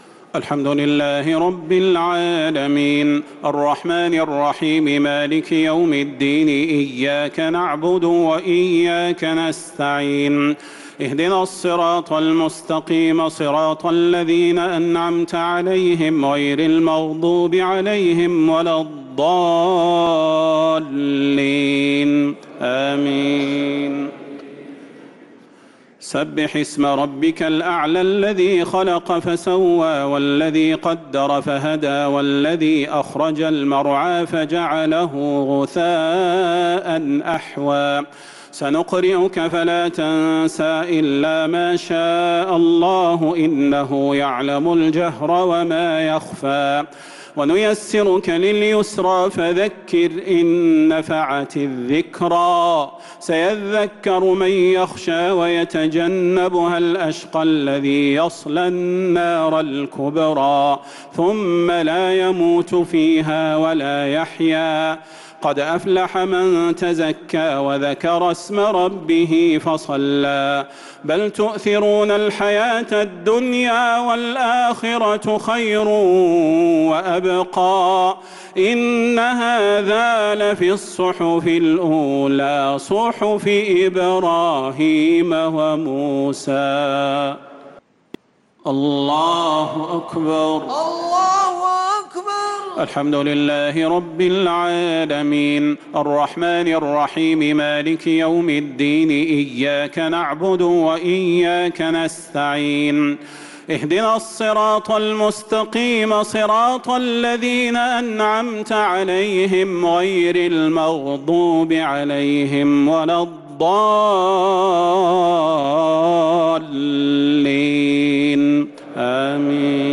الشفع و الوتر ليلة 9 رمضان 1446هـ | Witr 9th night Ramadan 1446H > تراويح الحرم النبوي عام 1446 🕌 > التراويح - تلاوات الحرمين